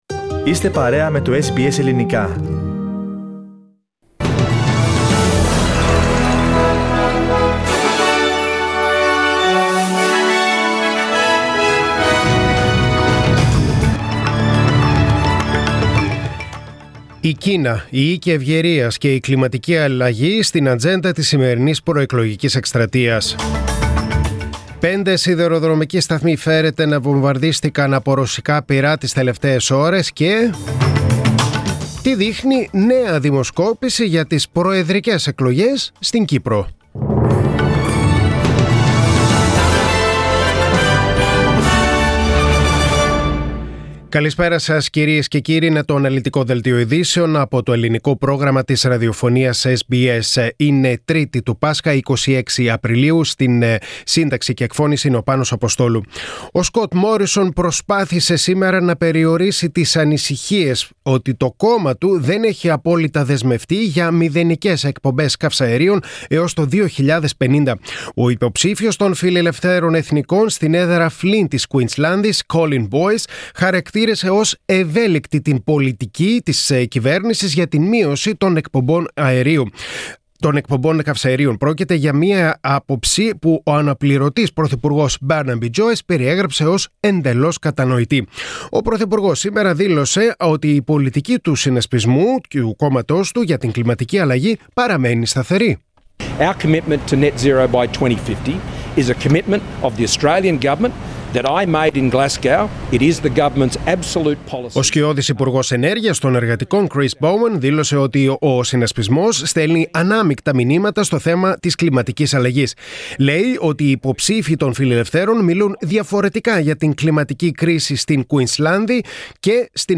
Δελτίο Ειδήσεων: Τρίτη 26.04.22